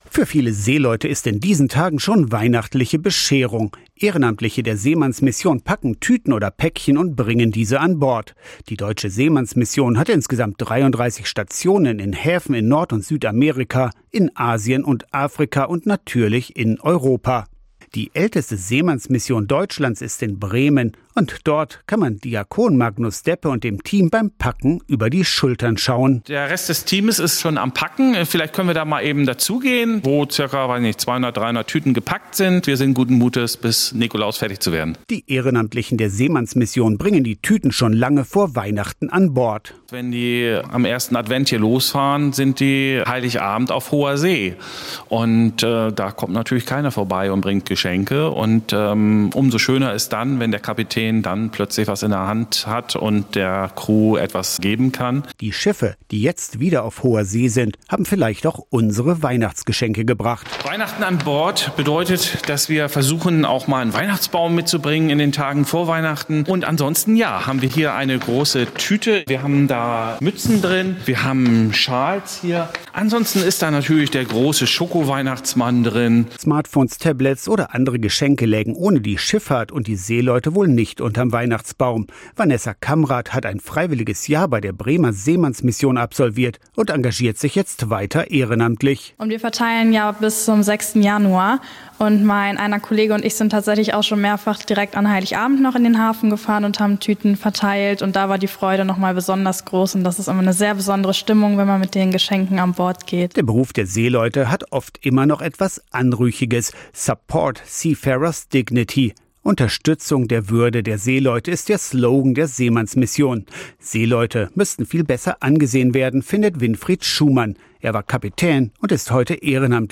iad-radio-saw-weihnachten-an-bord-seemannsmissionen-packen-weihnachtstueten-fuer-seeleute-44044.mp3